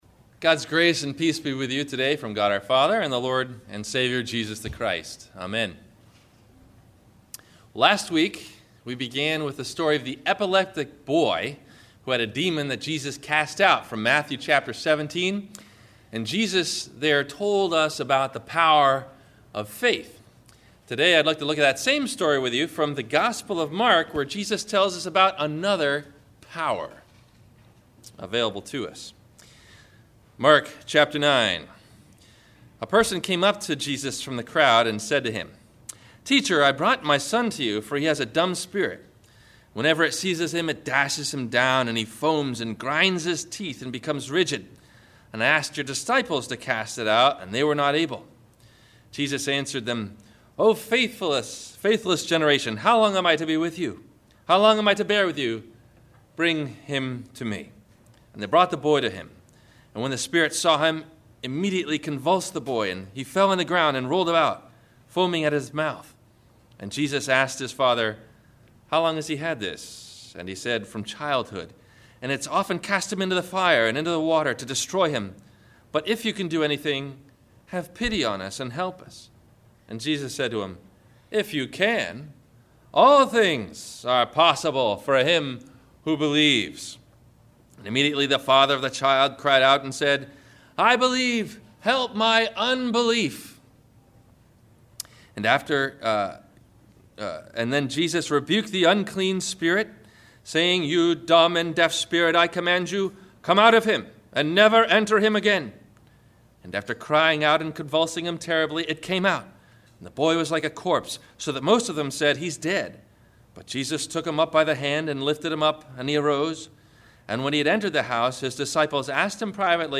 The Secret Power of Prayer – Sermon – September 16 2012